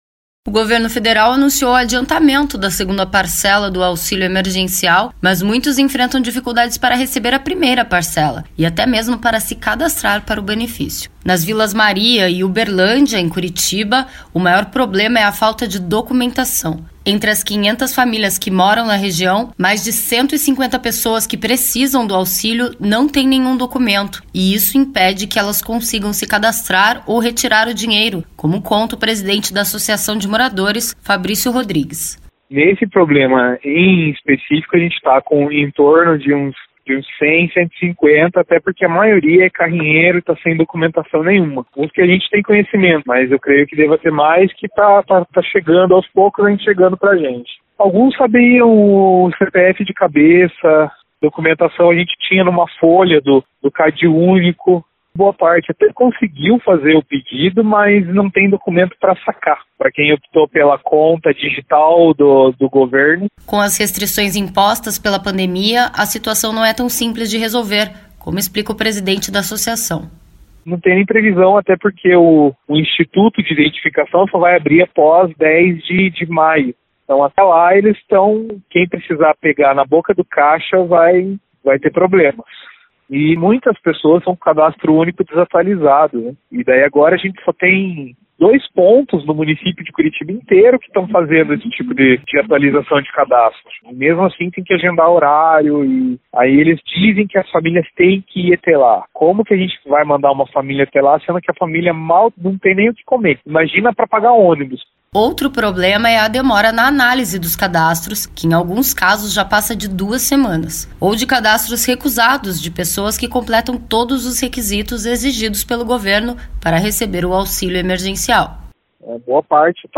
Nós entramos em contato com a Caixa Econômica Federal, mas, até o fechamento desta reportagem, a instituição não se posicionou.